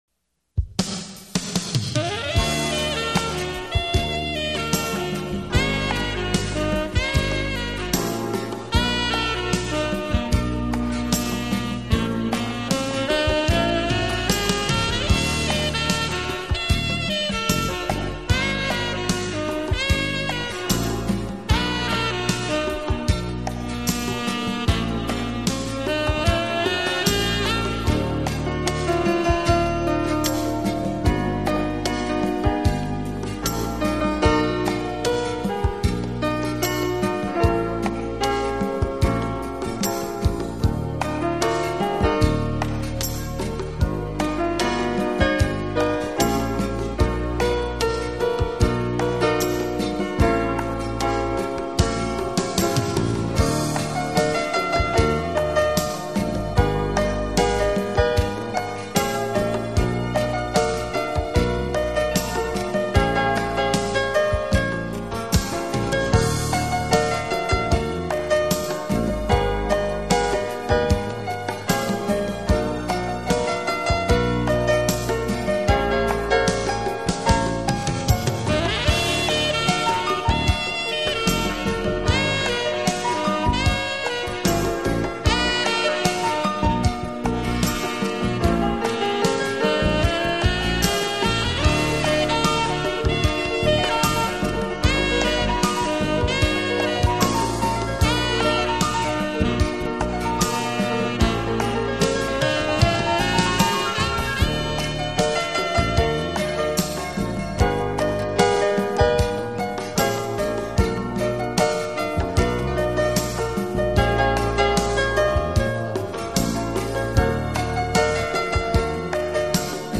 给朋友们送上一个迷人的情调音乐专辑，带领你我进入一个崭新浪漫和华
丽的音乐世界，音乐中曼妙的空间感便自然而然地完全展现！